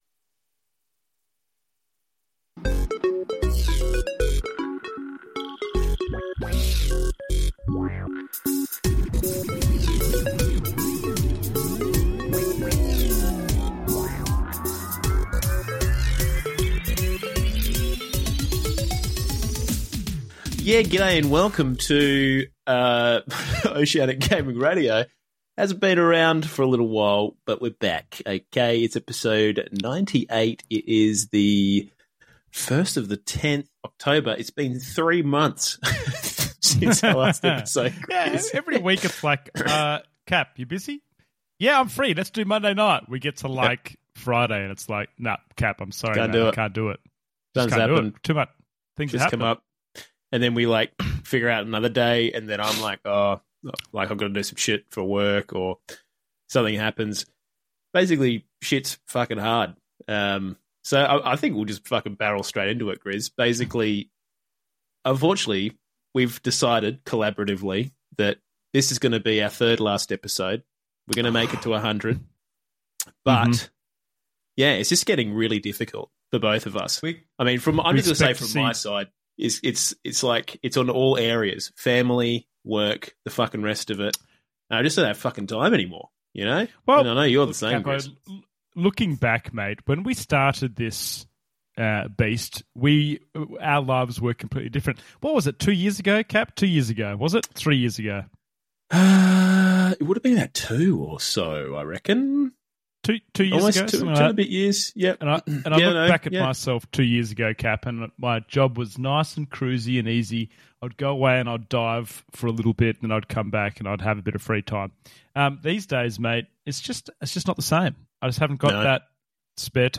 Welcome to Oceanic Gaming Radio, a fresh podcast curated by three Aussie content creators providing a new perspective on all things gaming.